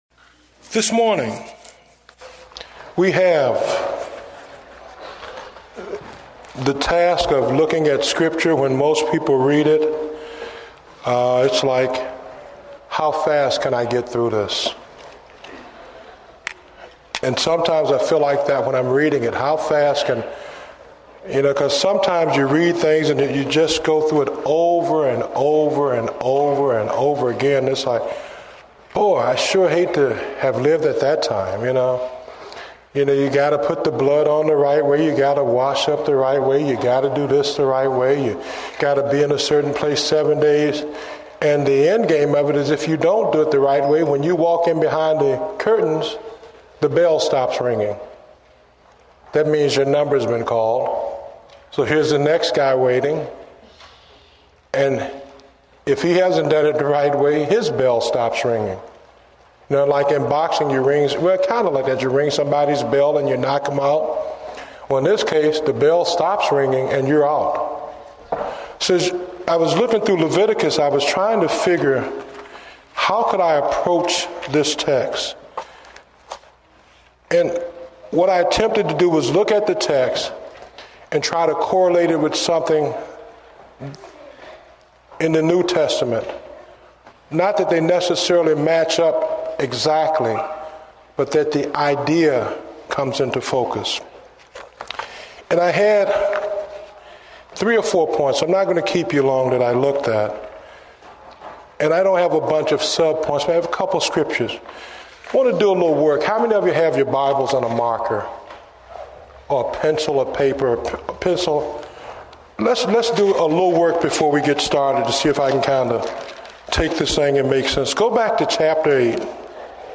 Date: February 15, 2009 (Morning Service)